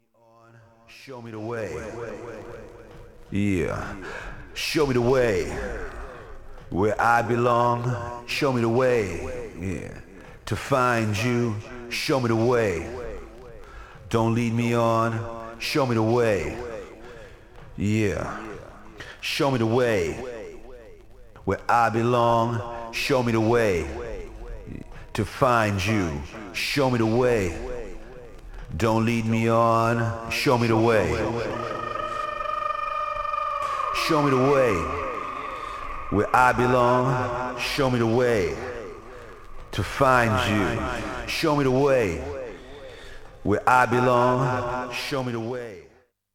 Styl: Progressive, House, Techno